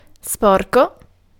Ääntäminen
IPA: /ˈspɔr.ko/